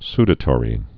(sdə-tôrē)